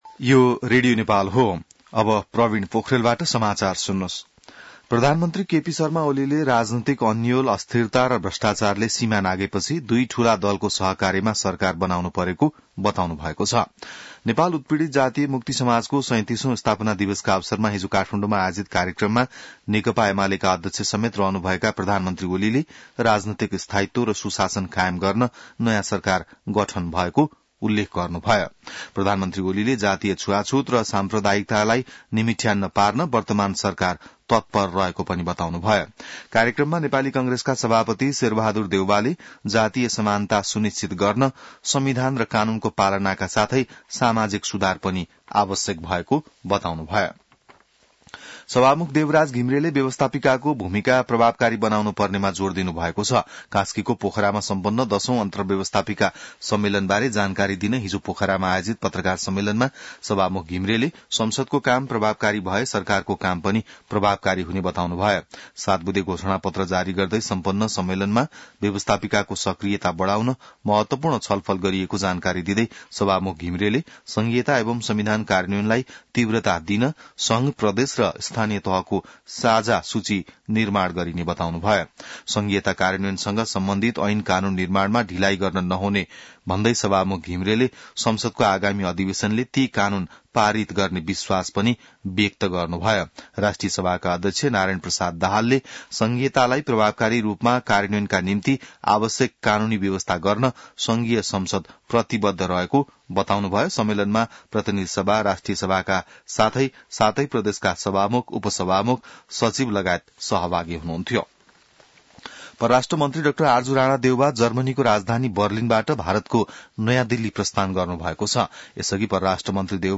बिहान ६ बजेको नेपाली समाचार : ५ पुष , २०८१